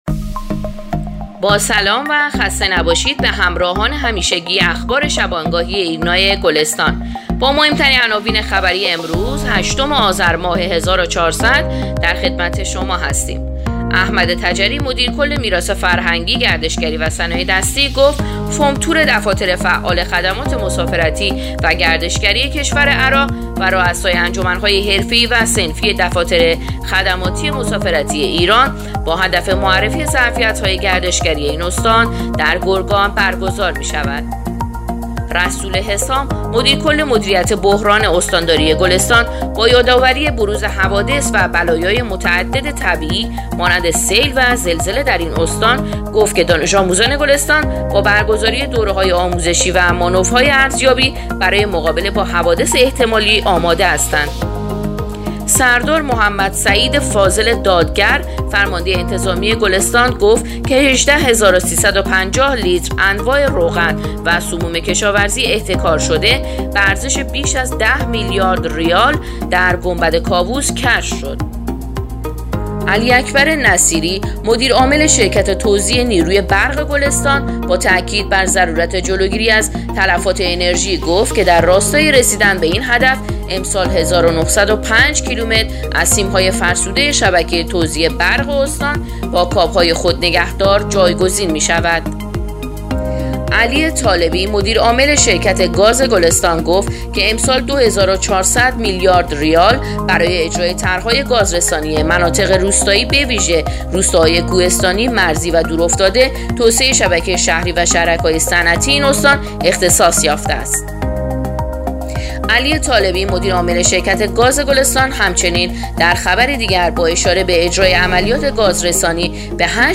پادکست/اخبار شبانگاهی هشتم آذر ایرنا گلستان